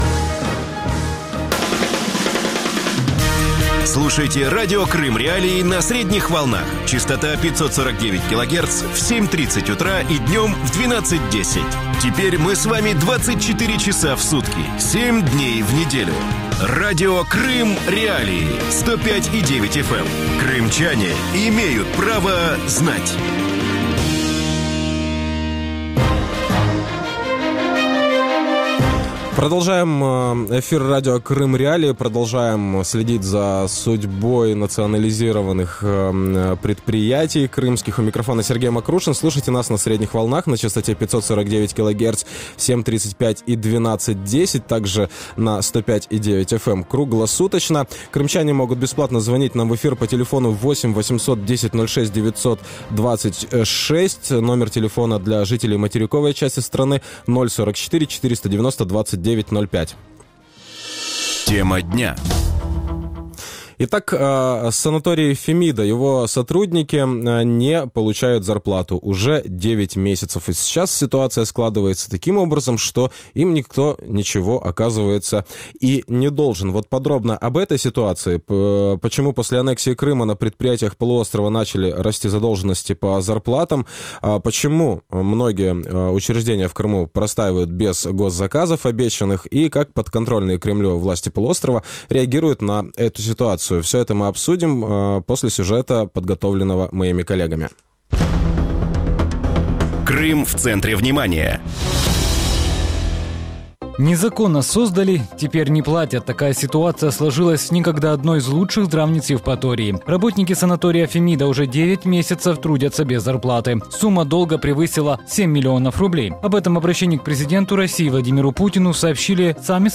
Почему после аннексии Крыма на предприятиях полуострова начали расти задолженности по заработным платам? Из-за чего многие государственные учреждения Крыма простаивают без заказов? Как подконтрольные Кремлю власти полуострова реагируют на сложившуюся ситуацию? Гости эфира: Павел Кудюкин, бывший замминистра труда и занятости населения России